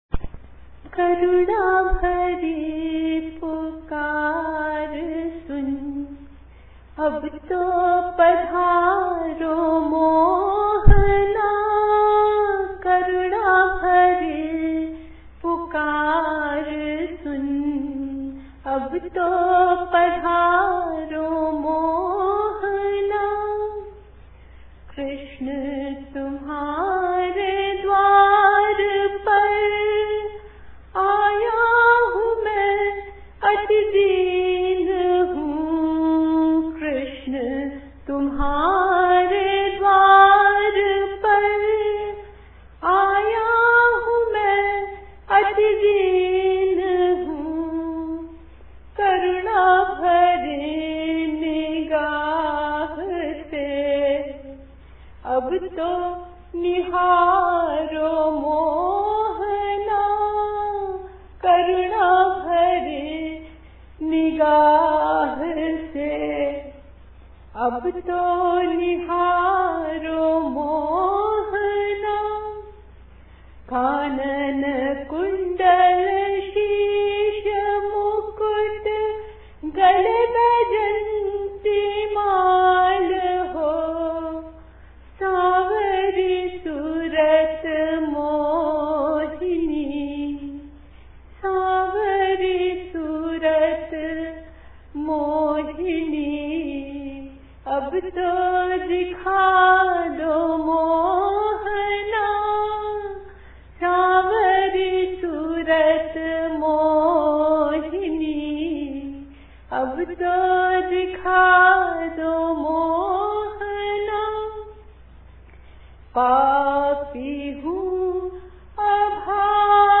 Kirtan